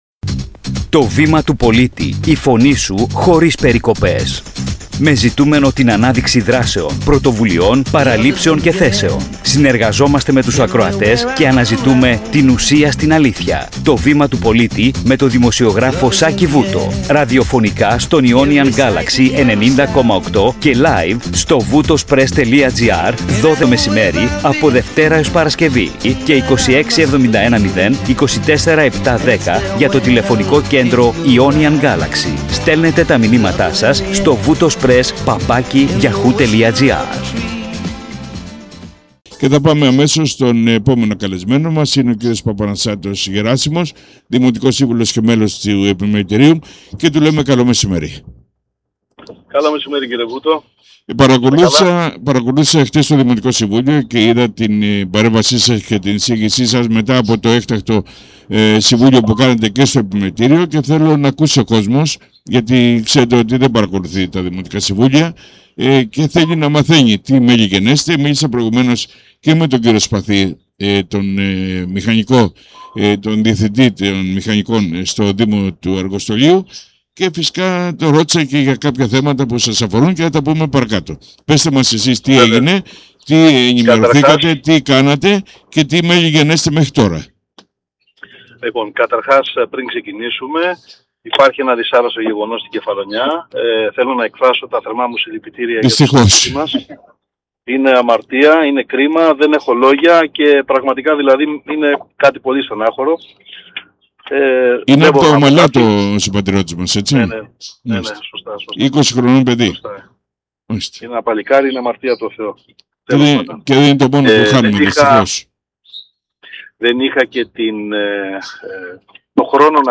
Ionian Galaxy 90.8 – «Το βήμα του πολίτη»